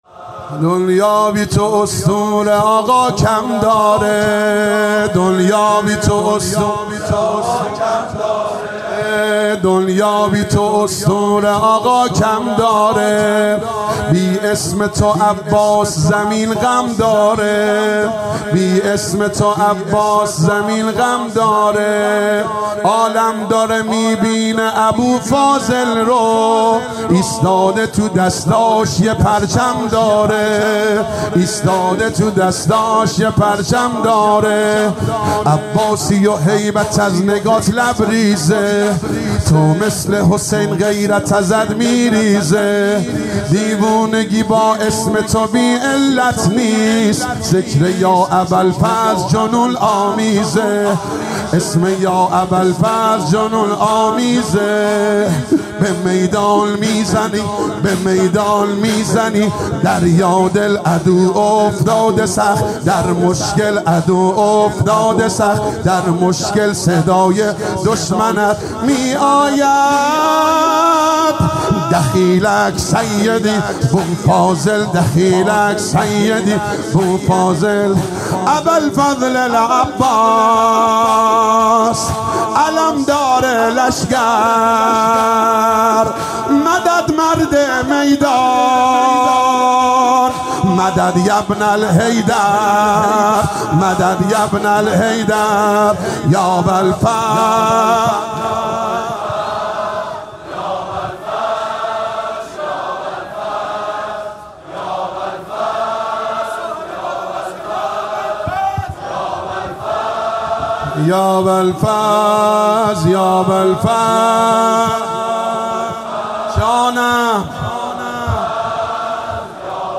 واحد | دنیا بی تو اسطوره آقا کم داره
مداحی
در شب نهم محرم الحرام 1441 / 1398